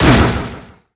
Cri de Shifours Style Poing Final dans Pokémon HOME.
Cri_0892_Poing_Final_HOME.ogg